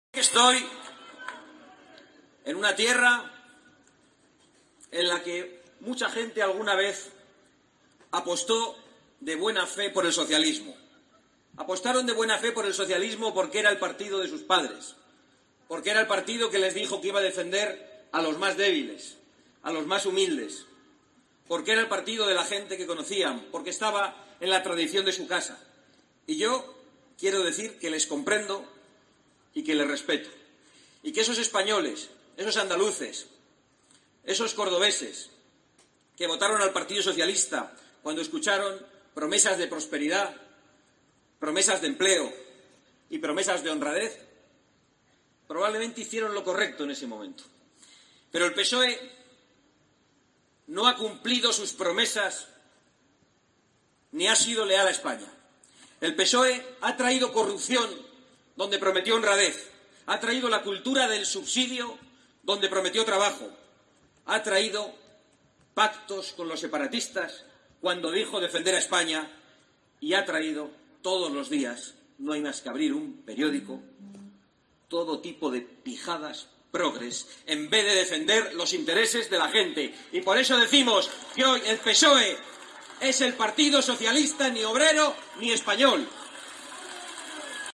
Así lo ha manifestado en un acto en Lucena (Córdoba) junto a la candidata a la Presidencia de la Junta por Vox, Macarena Olona, y la presidenta de Vox Madrid, Rocío Monasterio